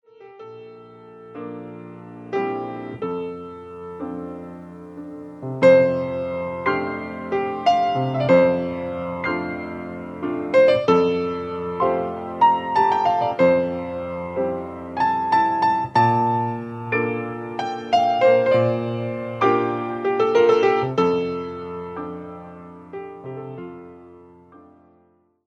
percussionist